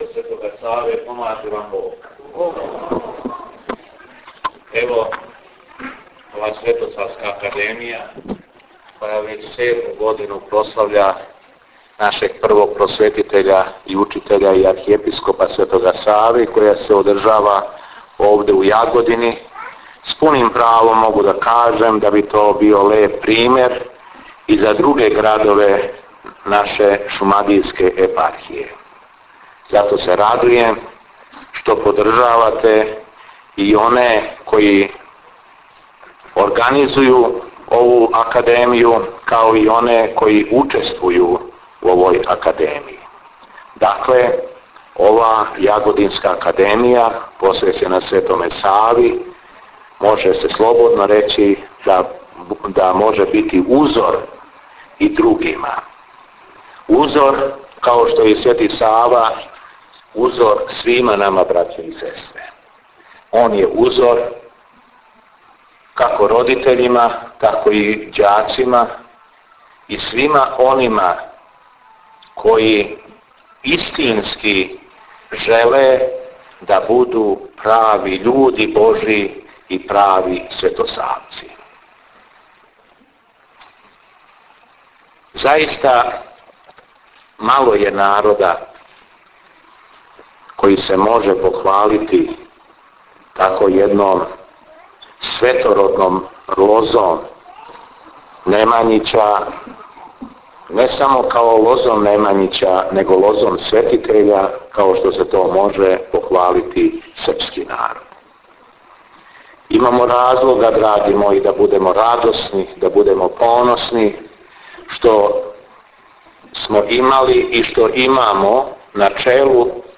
Беседа епископа шумадијског Г. Јована на Светосавској академији у Јагодини